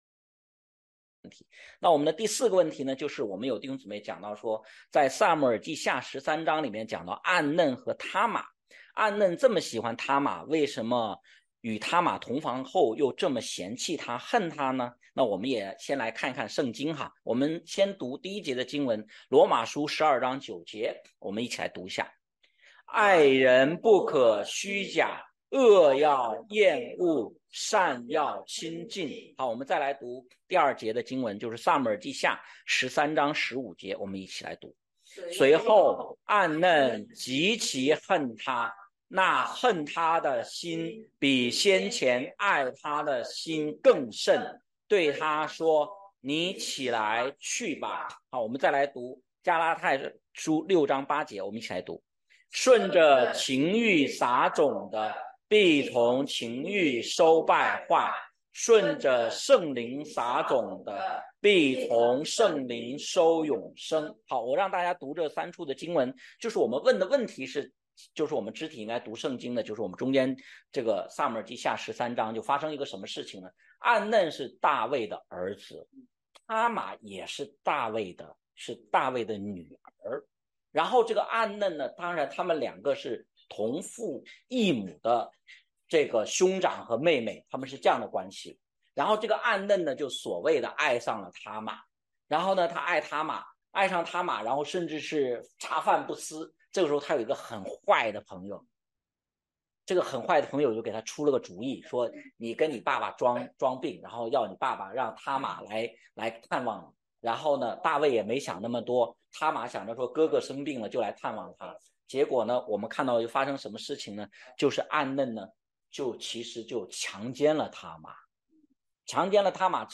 问题解答录音